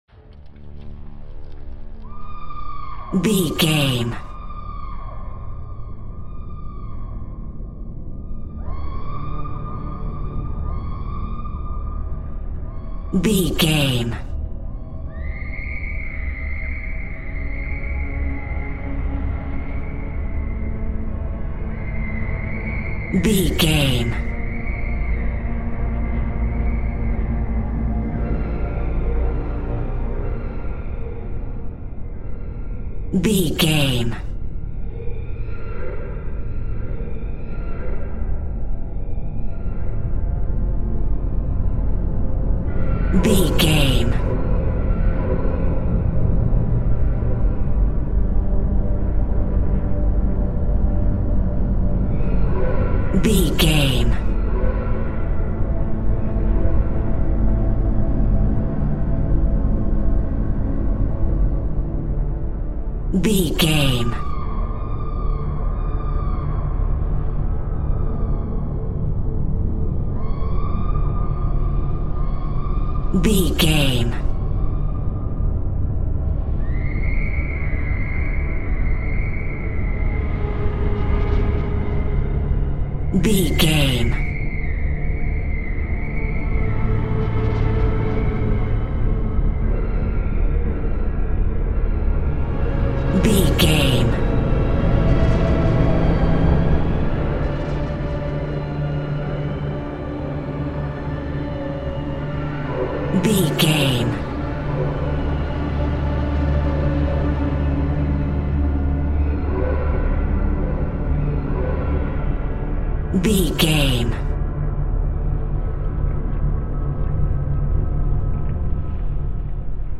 Atonal
ominous
dark
eerie
strings
Horror Pads
Horror Synth Ambience